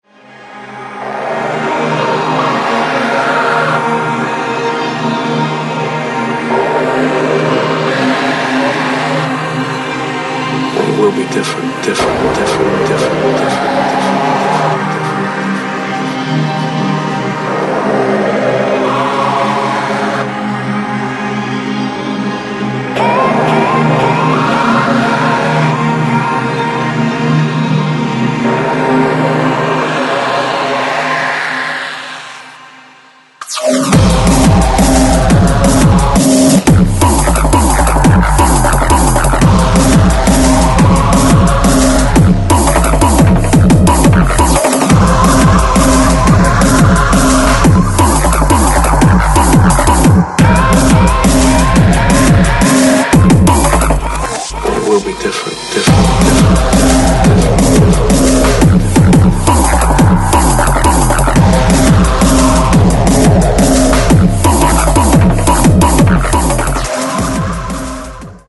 Styl: Drum'n'bass, Hardtek/Hardcore